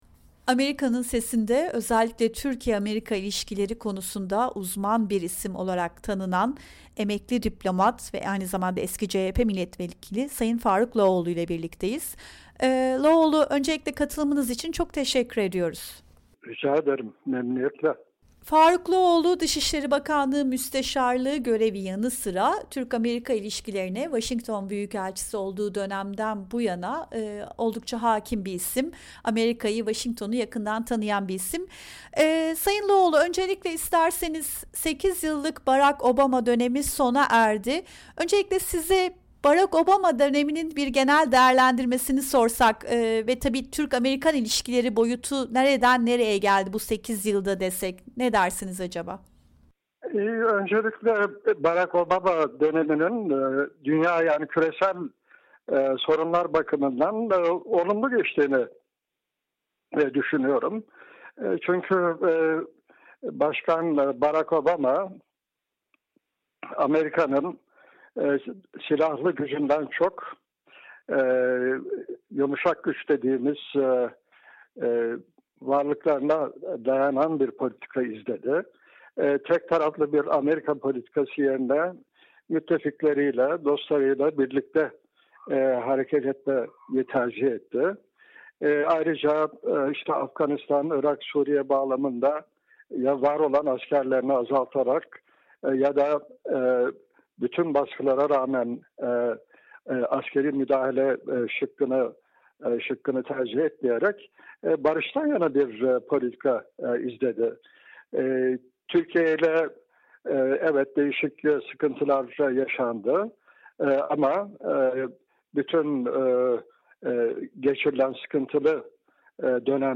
Faruk Loğoğlu ile Söyleşi